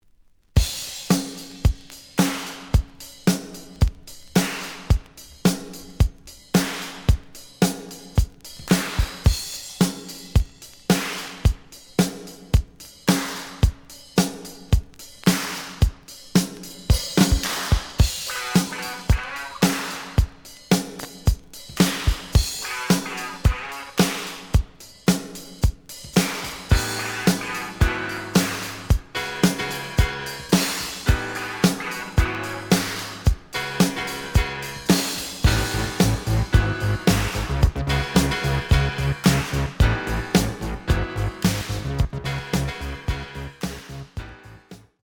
The audio sample is recorded from the actual item.
●Genre: Disco
●Record Grading: VG~VG+ (傷はあるが、プレイはおおむね良好。Plays good.)